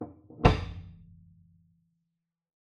Oven Door Old Sound
household